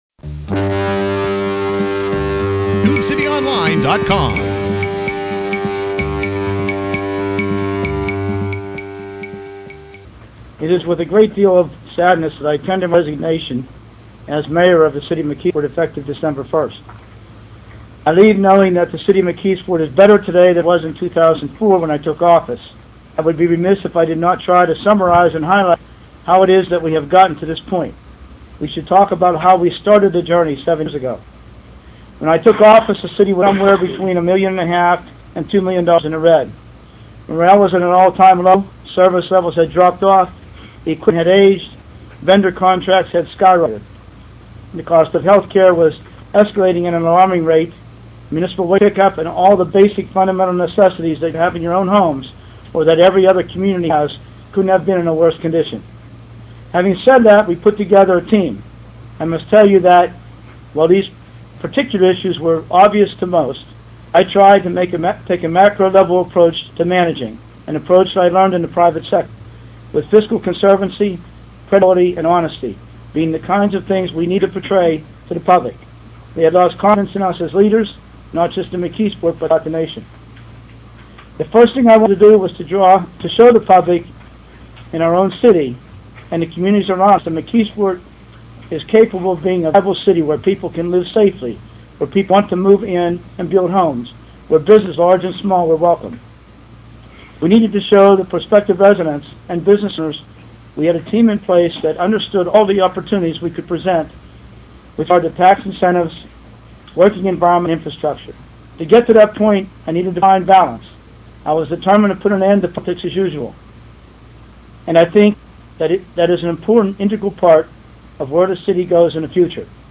Reading aloud from his resignation letter, Brewster thanked council, department heads and employees for saving the city from possible Act 47 municipal bankruptcy.
Mayor Jim Brewster resignation, Dec. 1, 2010.mp3